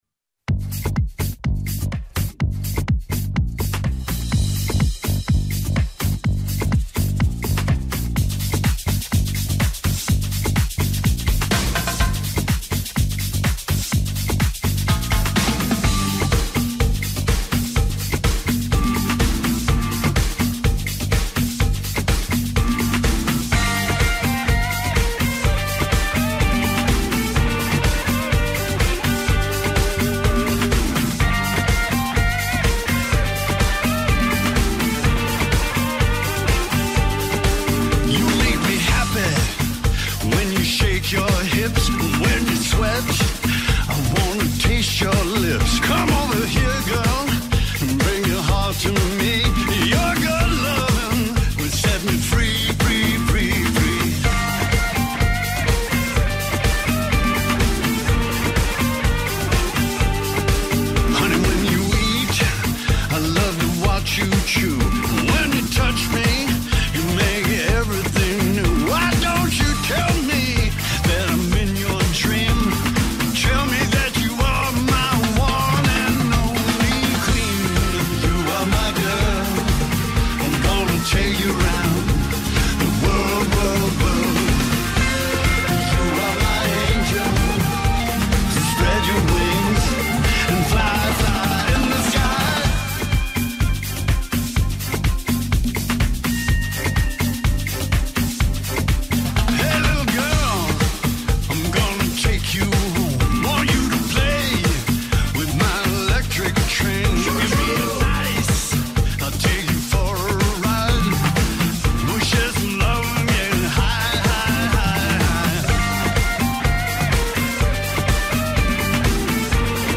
Delta blues
ΣΥΝΕΝΤΕΥΞΗ